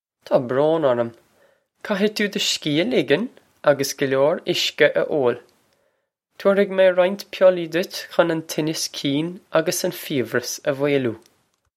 Pronunciation for how to say
Taw bro-in orr-um. Kah-hig too duh shkee ah lig-un a-gus guh lyore ish-ka ah oh-l. Tow-er-hig mey rye-inch pill-ee ditch khun on cheen-as keen a-gus on feev-rus ah vwale-oo.